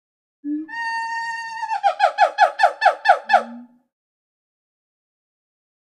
Gibbon Call. One Short Hoot Followed By A Series Of High Pitched Yelps. Close Perspective.